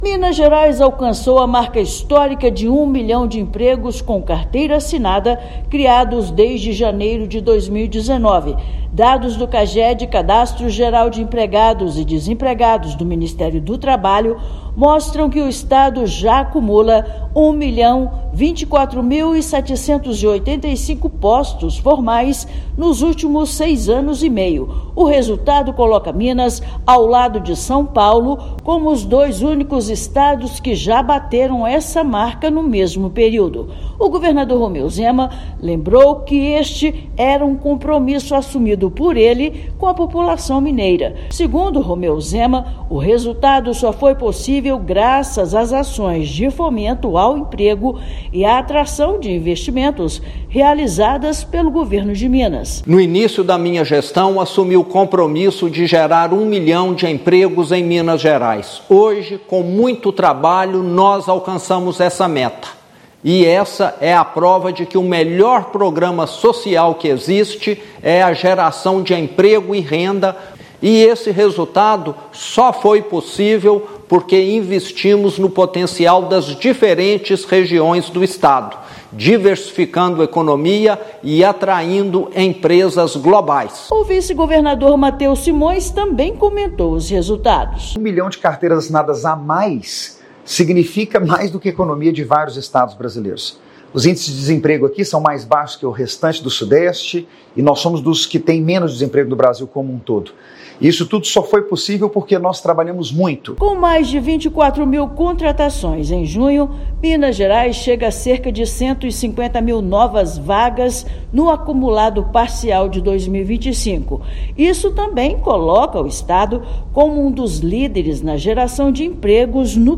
[RÁDIO] Minas Gerais ultrapassa marca histórica de 1 milhão de empregos formais criados desde 2019
Ações do Governo de Minas garantiram posição do estado nos primeiros lugares de geração de oportunidades ao longo dos últimos seis anos e meio. Ouça matéria de rádio.